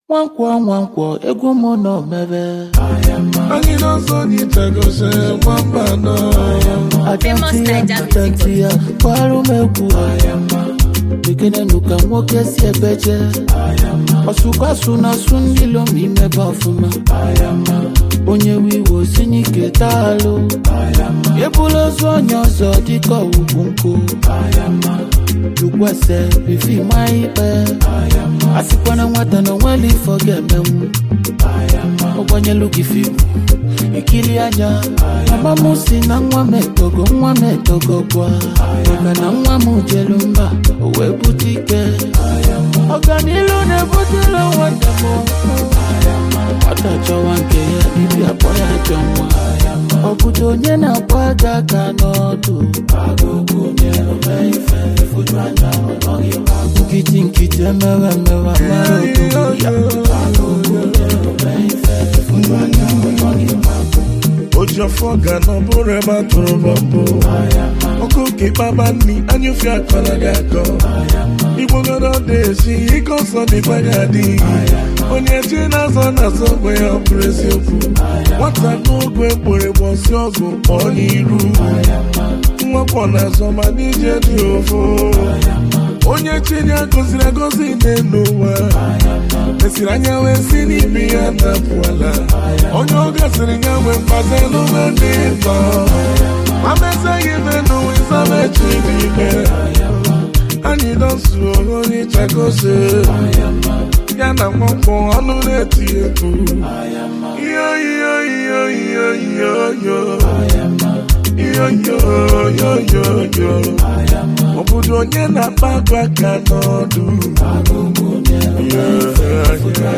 a notable Nigerian highlife vocalist and performer.